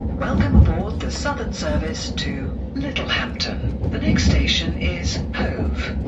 announcement recorded on a Class 377